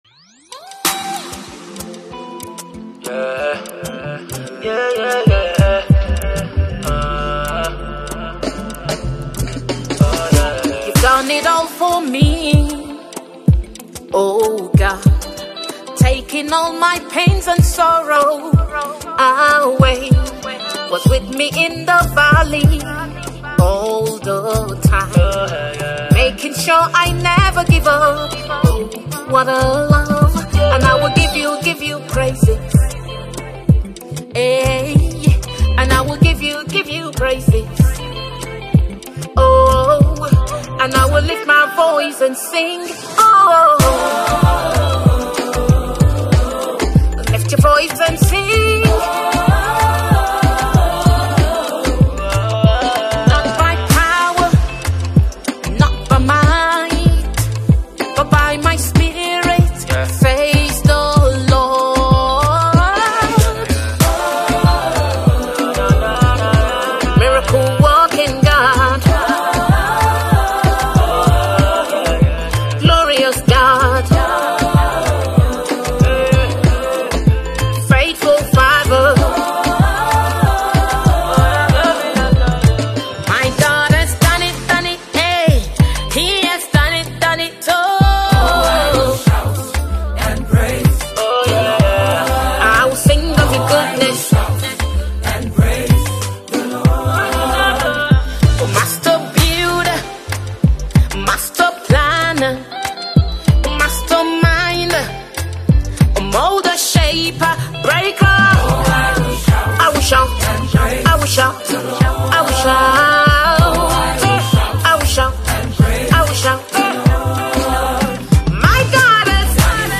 Nigerian gospel minister and songwriter
praise song